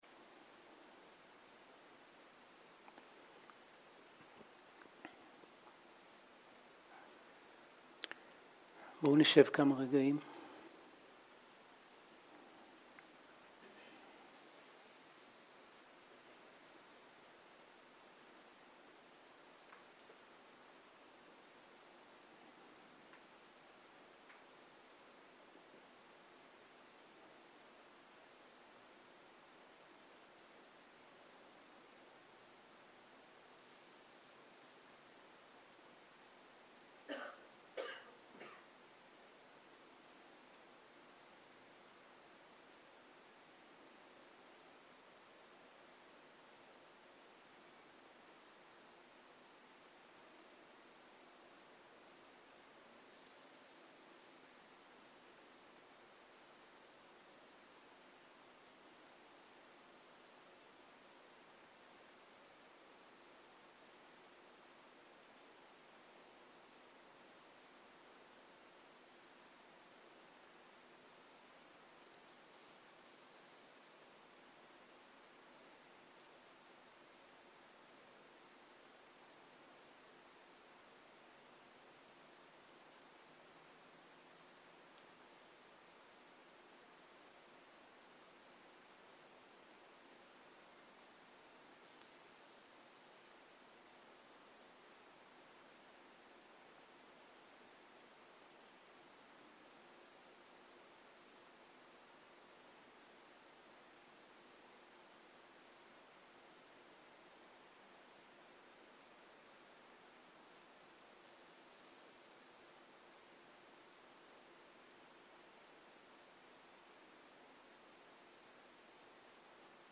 ערב - שיחת דהרמה - הדרך לשינוי.
סוג ההקלטה: שיחות דהרמה